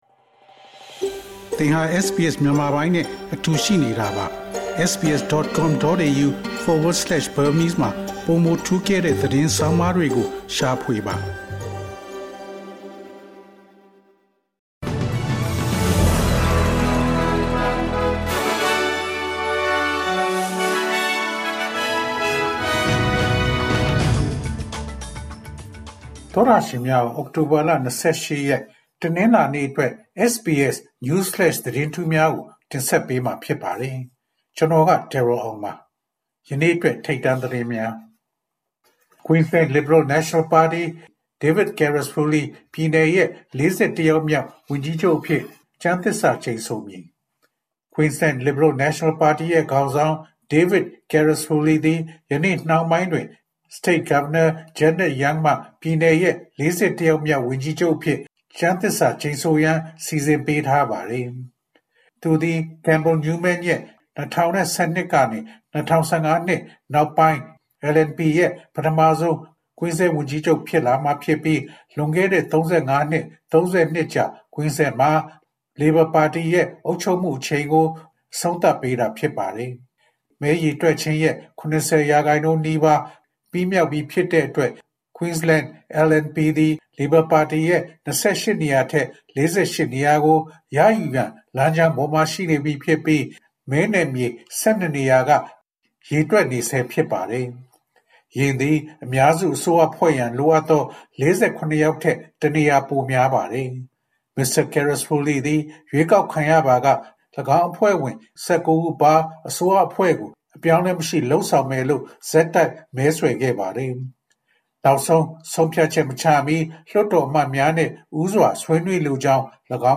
SBS မြန်မာ ၂၀၂၄ နှစ် အောက်တိုဘာလ ၂၈ ရက် News Flash သတင်းများ။